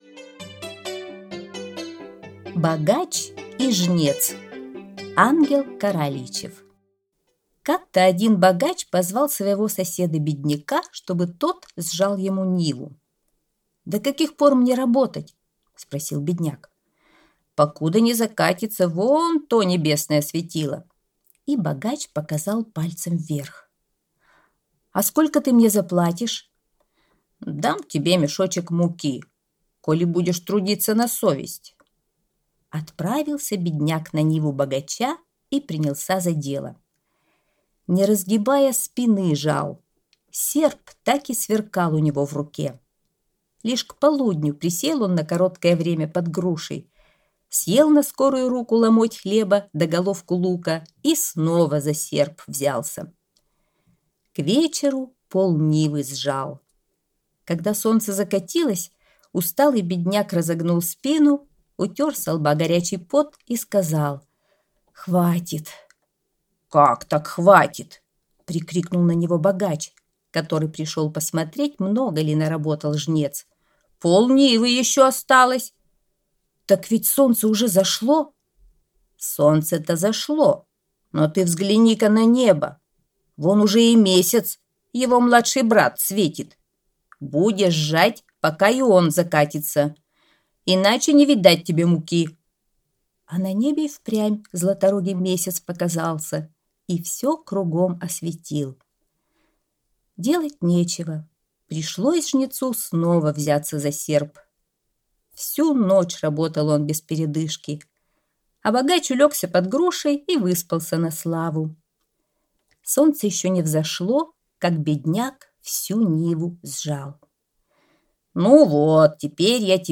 Богач и жнец - аудиосказка Ангела Каралийчева - слушать онлайн | Мишкины книжки